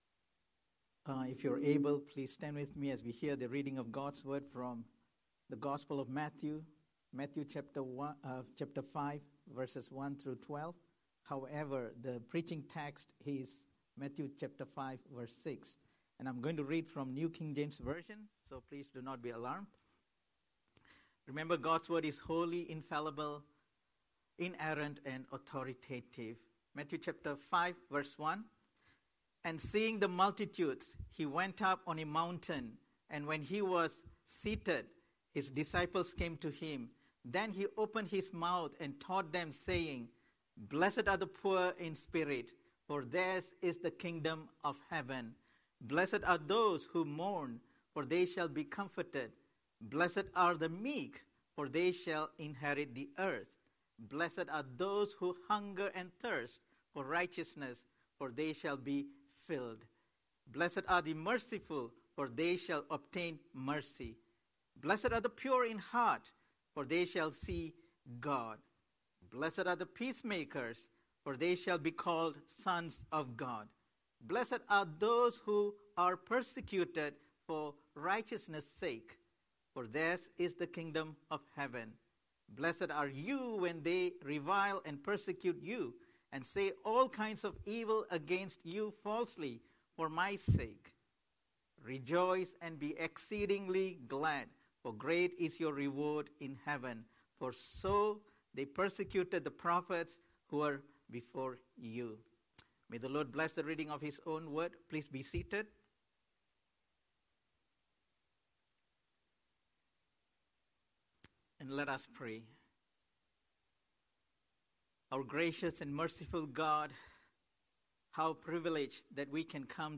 Scripture: Matthew 5:6 Series: Sunday Sermon